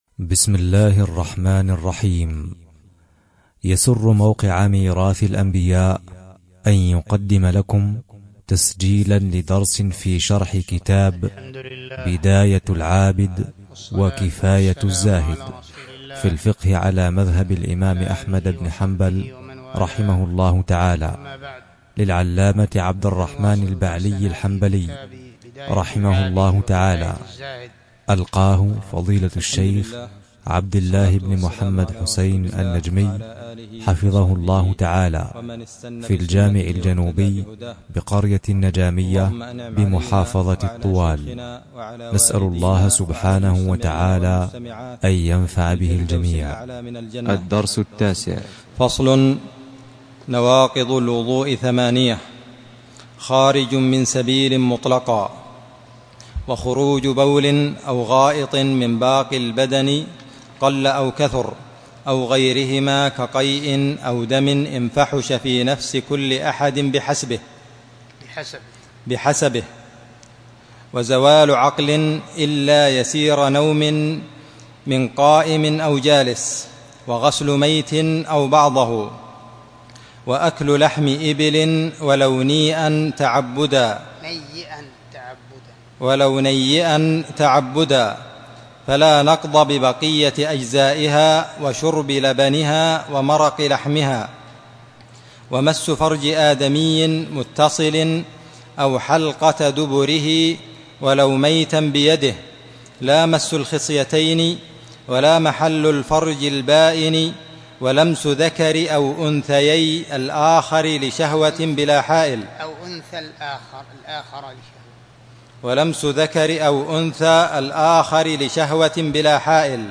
شرح بداية العابد وكفاية الزاهد في الفقه الدرس 9